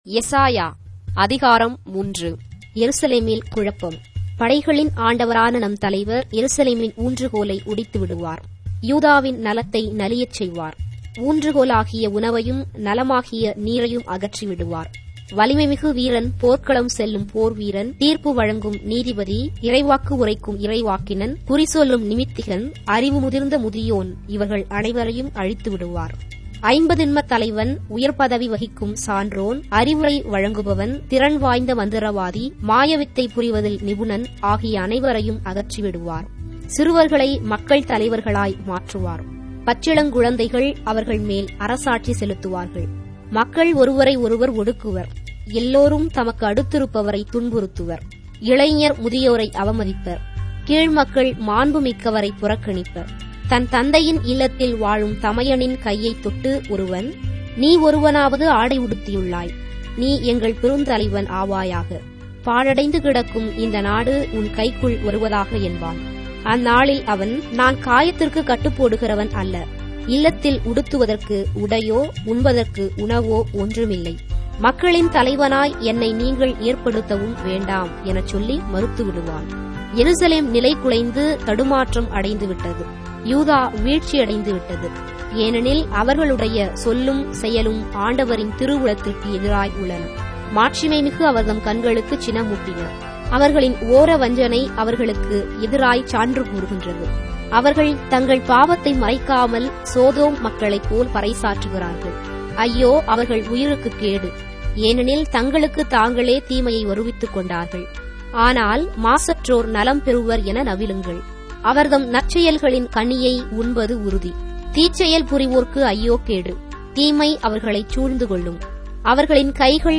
Tamil Audio Bible - Isaiah 29 in Ecta bible version